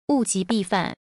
Wù jí bì fǎn